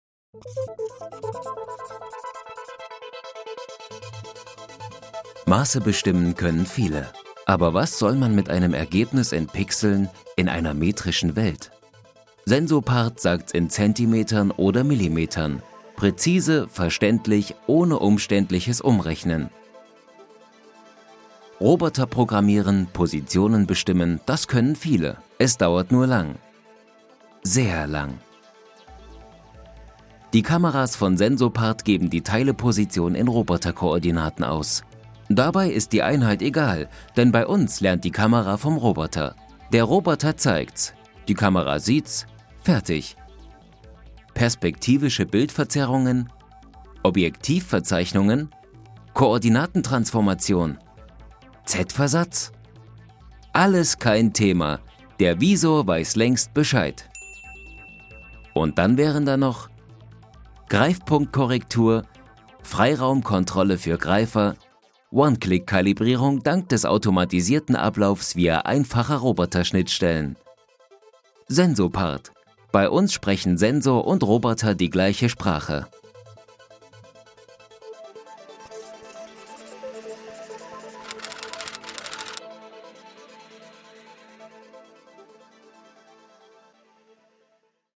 Professioneller Sprecher - Schnelle Bearbeitung - Eigenes Studio
Kein Dialekt
Sprechprobe: Industrie (Muttersprache):